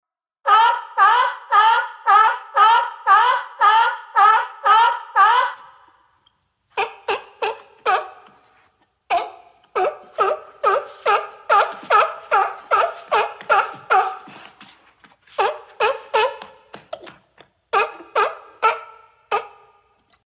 На этой странице собраны звуки морских котиков — забавные и живые голоса этих удивительных животных.
Морской котик ведет беседу